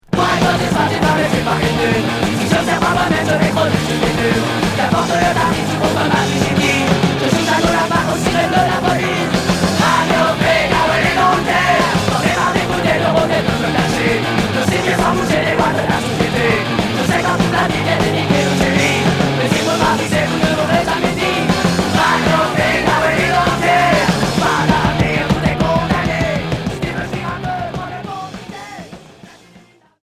Punk rock Deuxième 45t